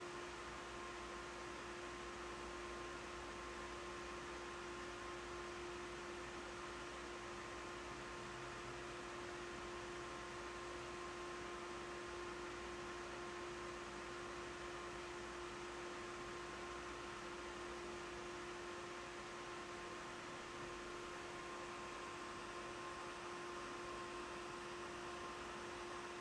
All clips were recorded under the same conditions, using an iPhone 16 Pro placed 3 feet away from the fan, with the fan running at full speed and blowing away from the microphone.